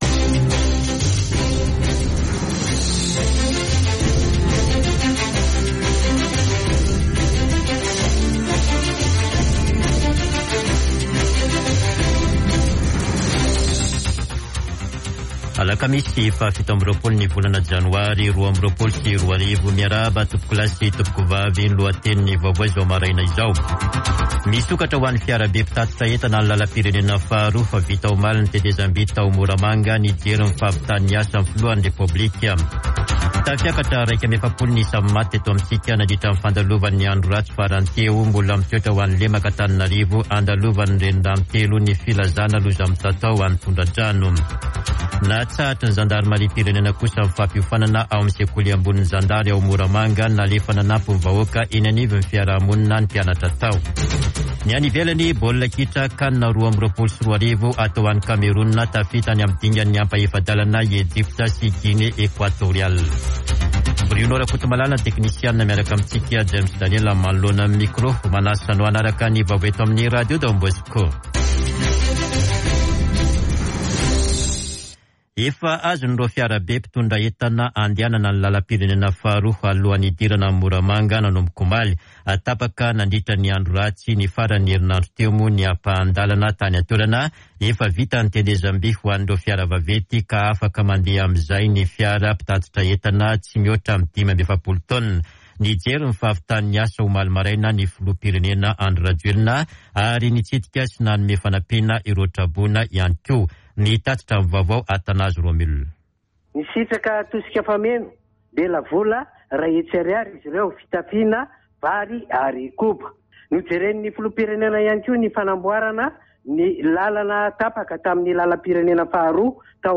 [Vaovao maraina] Alakamisy 27 janoary 2022